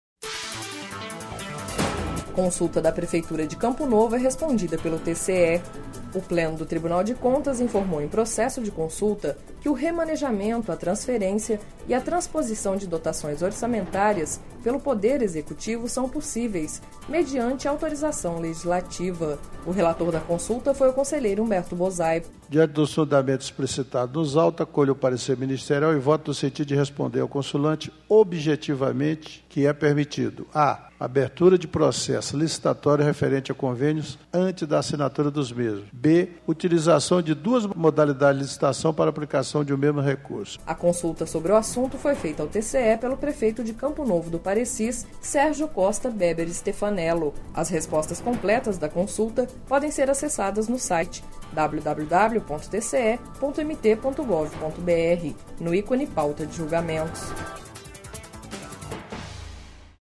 Sonora: Humberto Bosaipo – conselheiro do TCE-MT